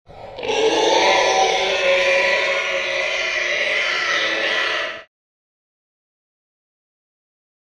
Alien Scream; Angry Or Anguished Large Creature Squeal.